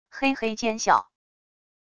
嘿嘿奸笑wav音频